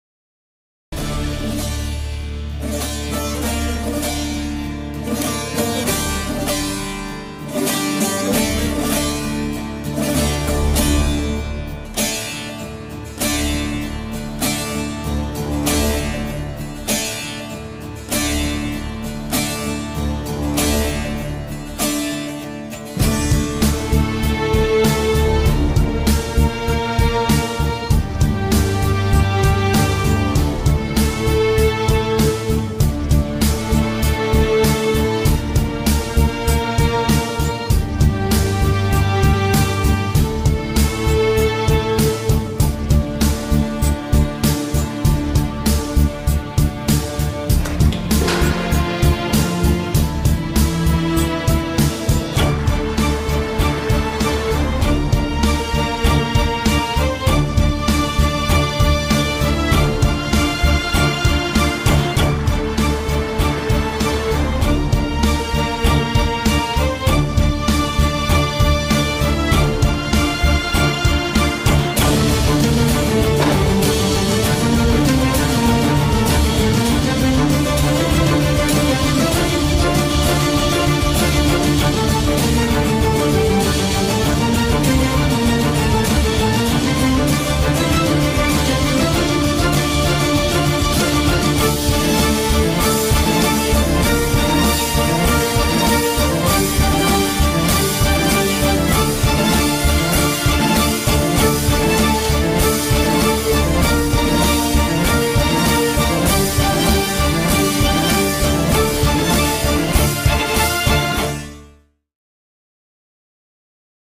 tema dizi müziği, duygusal heyecan aksiyon fon müzik.